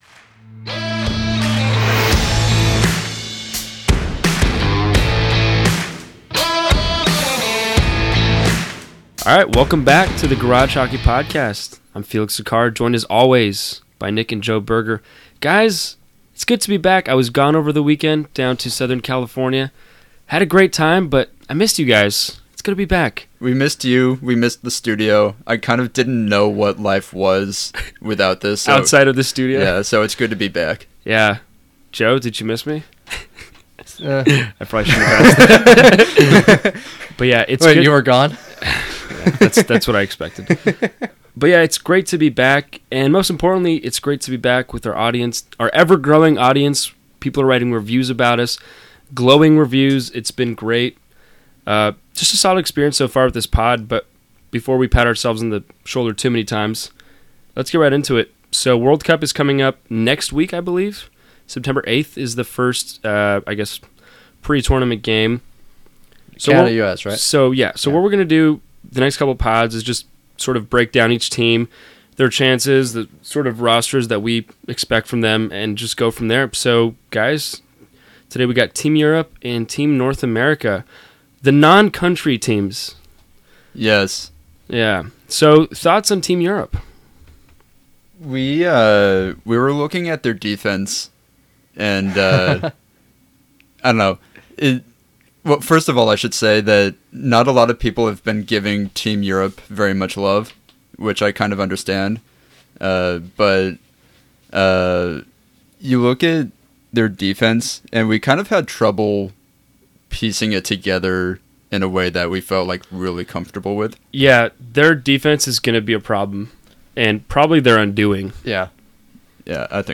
The lads are back in the studio to start their deep dive into the World Cup of Hockey.